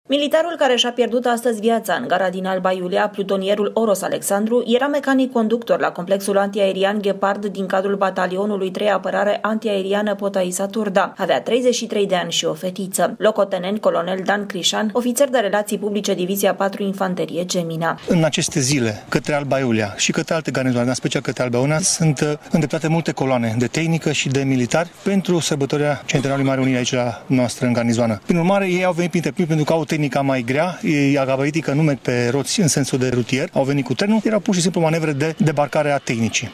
Corespondenta RRA